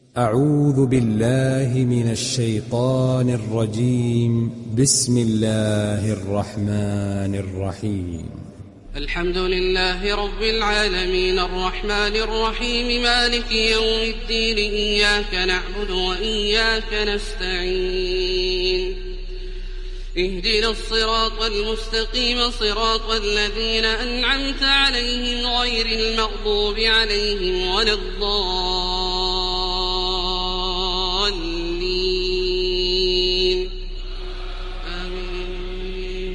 ডাউনলোড সূরা আল-ফাতিহা Taraweeh Makkah 1430